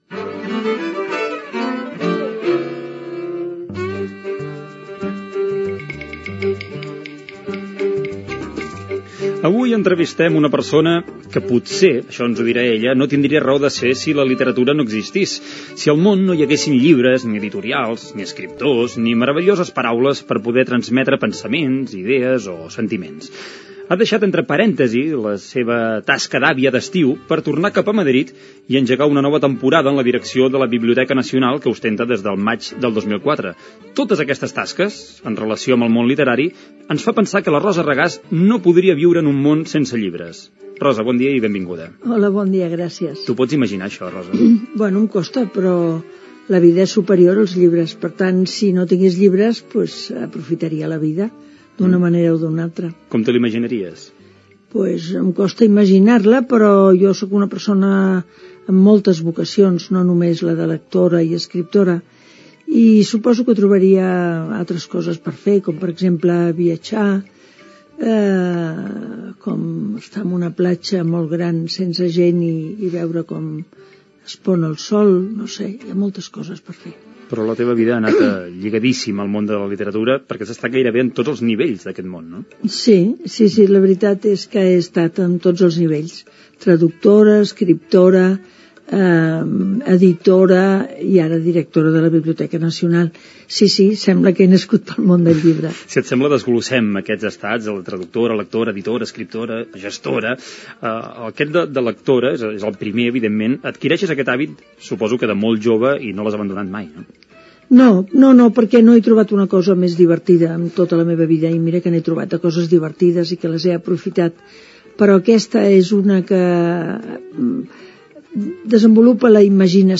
Entrevista a la directora de la Biblioteca Nacional d'Espanya, Rosa Regàs. Parla de la seva experiència com a lectora i editora.